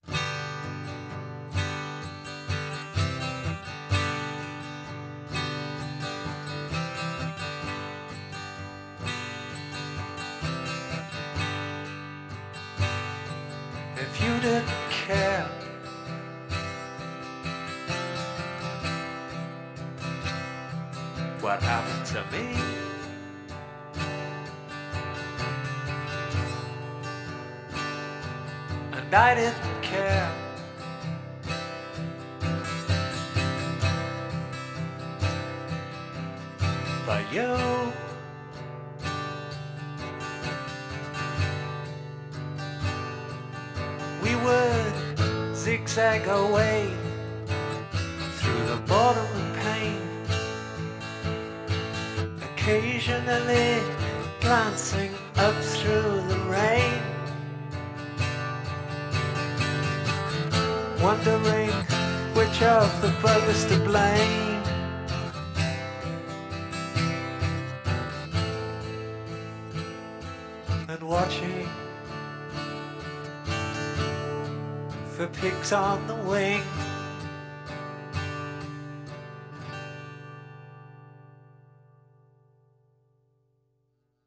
Progressive Rock, Art Rock